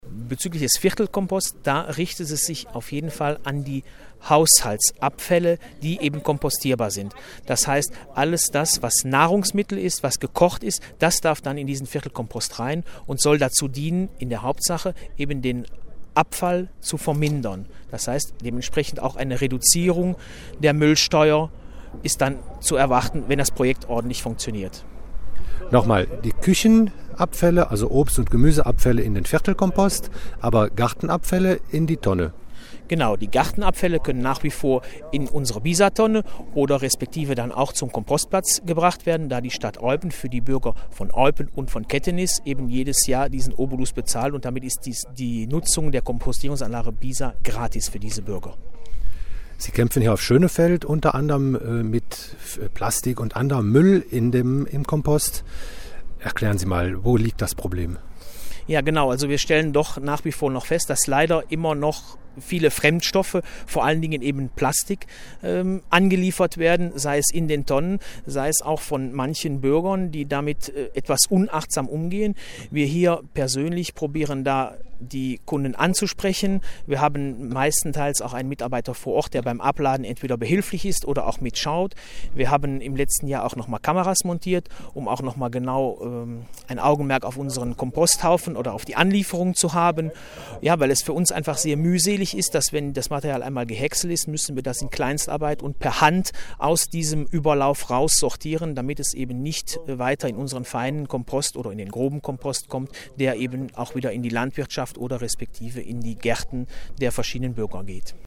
Was darf in den Viertelkomposthaufen rein? GE-Redakteur